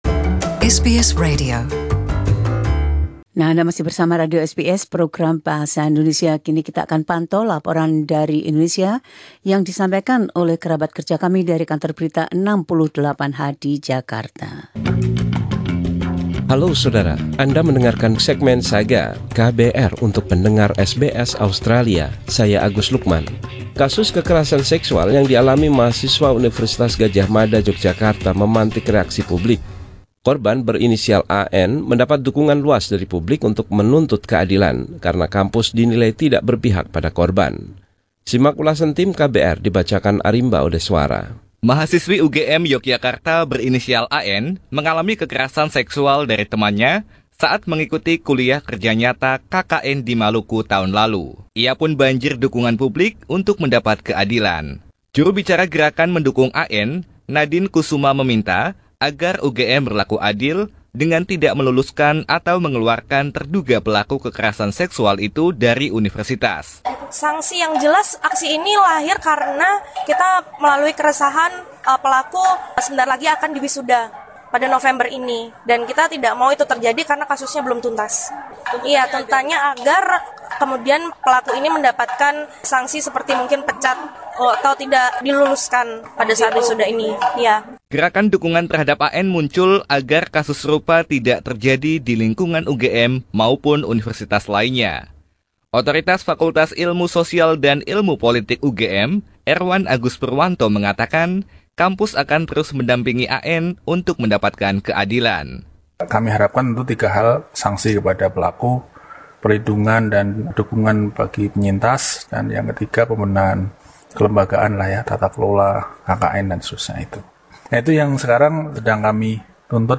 Laporan KBR 68H: Kekerasan Seksual.